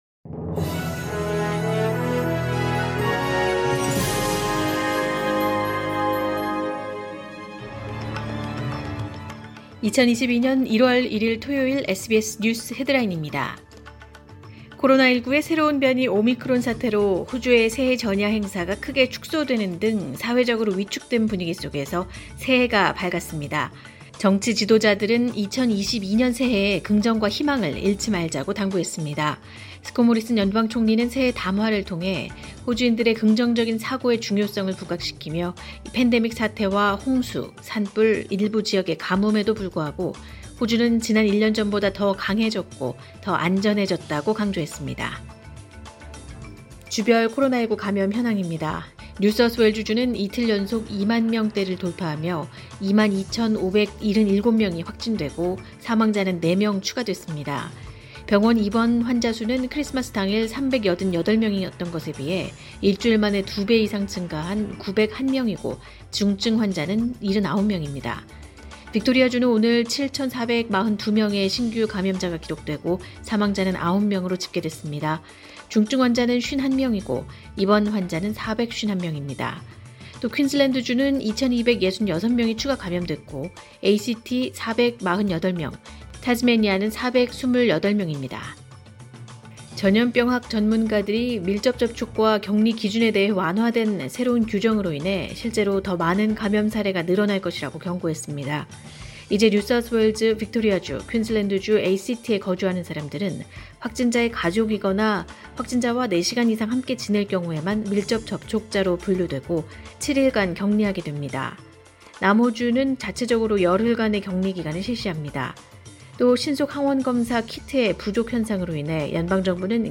2022년 1월 1일 토요일 새해 첫날 SBS 뉴스 헤드라인입니다.